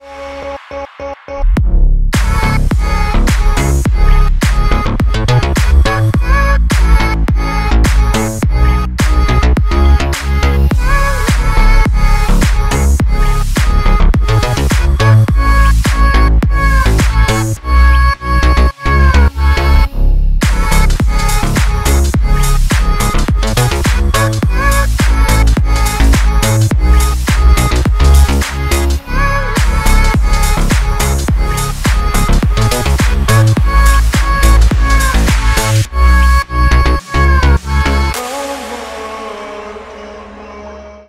Ремикс
без слов # клубные